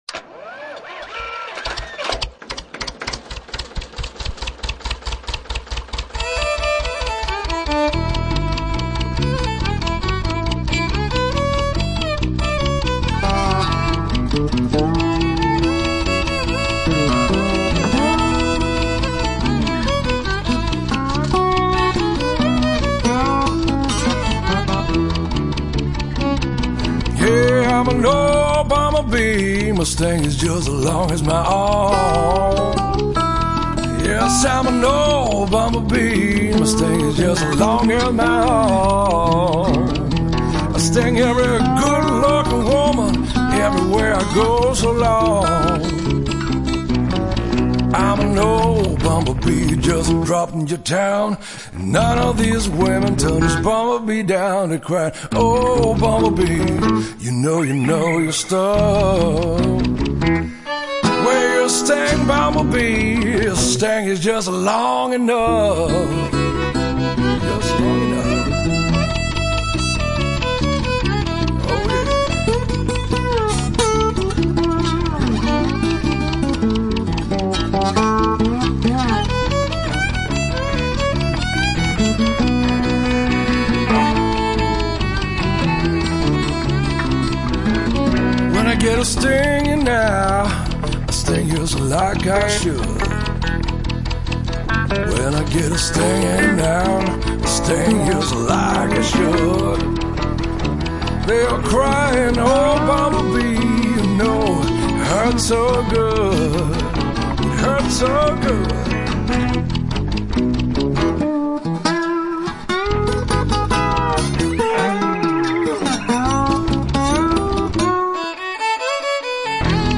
Yup, here's a really heavy hittin' coolest of cool drummer!
fiddle